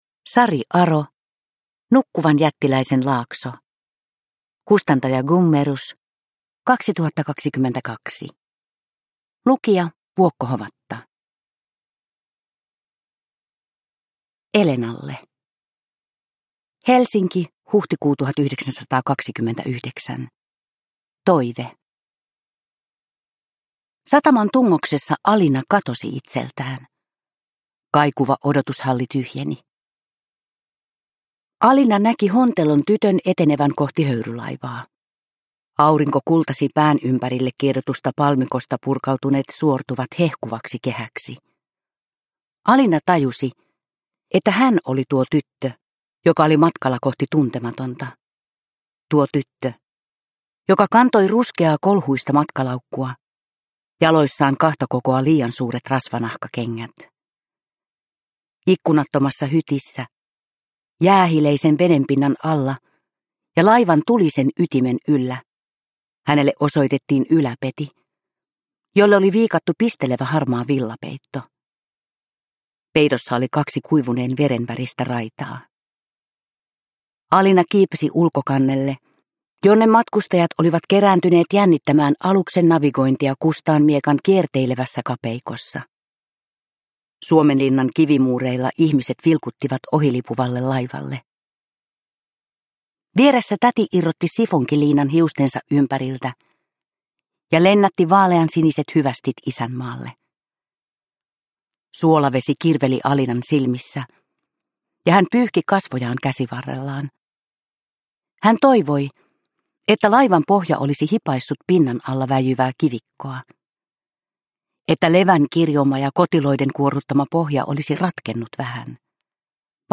Nukkuvan jättiläisen laakso – Ljudbok – Laddas ner